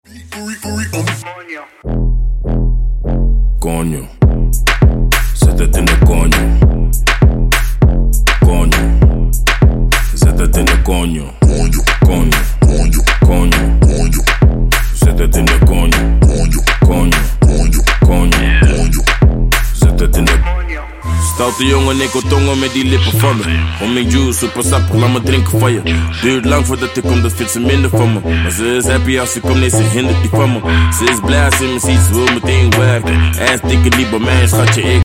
• Качество: 128, Stereo
ритмичные
dancehall
EDM
мощные басы
Bass House
Moombahton
низкий мужской голос